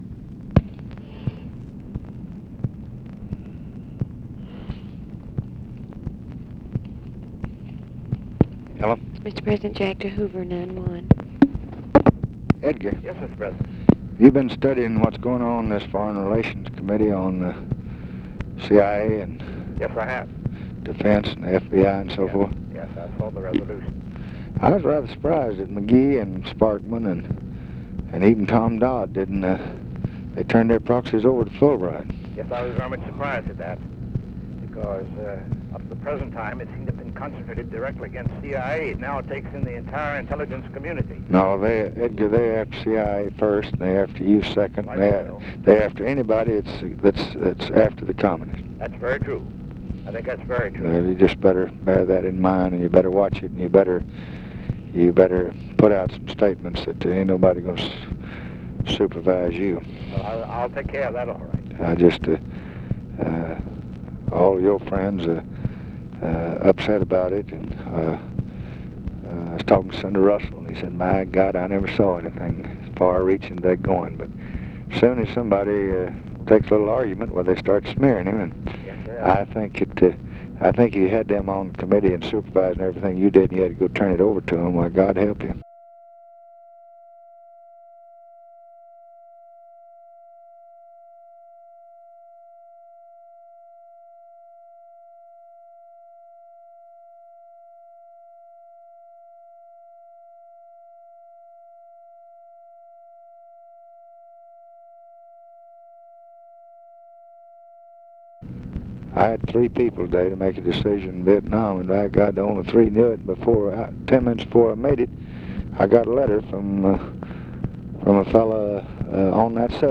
Conversation with J. EDGAR HOOVER, May 13, 1966
Secret White House Tapes